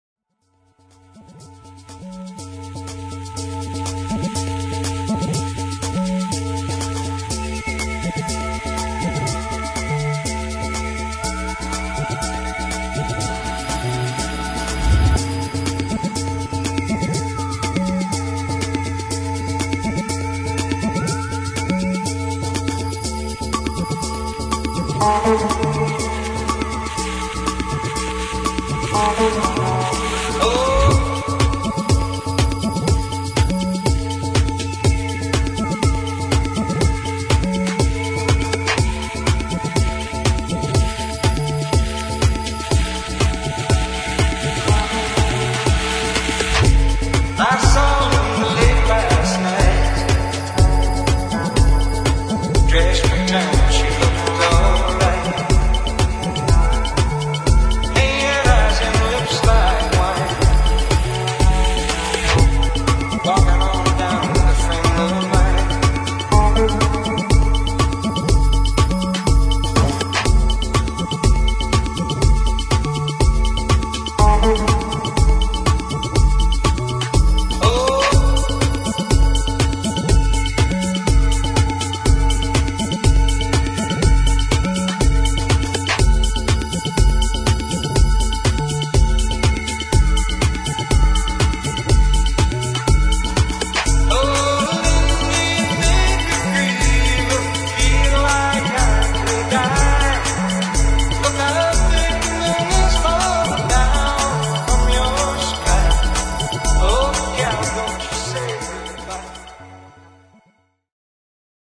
[ HOUSE / EDIT ]